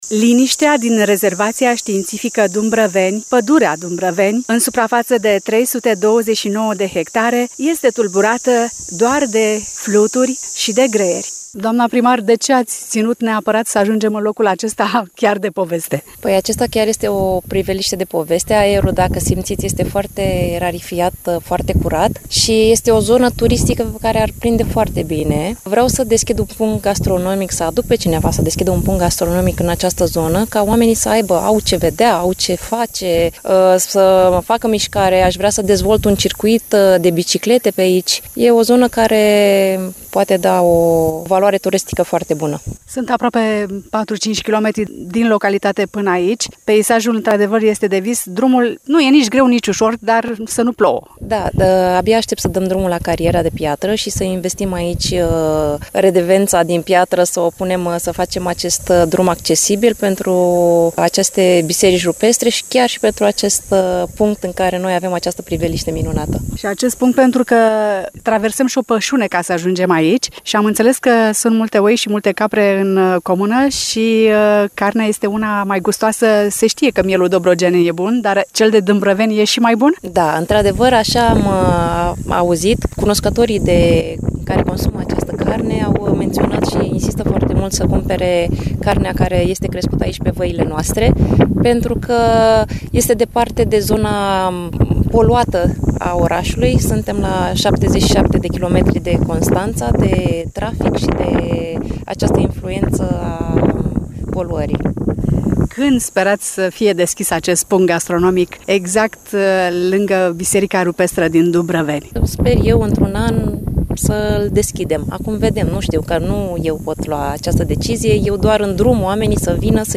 După ce la 1 mai, de Ziua comunei a reușit să aducă peste 5000 de vizitatori, un punct gastronomic și un traseu pentru bicicliști pe platoul din preajma Bisericii Rupestre Dumbrăveni, ce datează din secolul al IV-lea, este ceea ce își dorește primarul comunei, Luminița Șandru. Carnea de miel și brânzeturile localnicilor ar fi unele speciale, susține tânăra primăriță ce a copilărit pe aceste meleaguri cu formațiuni calcaroase ce crează peisaje spectaculoase.